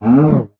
minecraft / sounds / mob / cow / hurt1.ogg
hurt1.ogg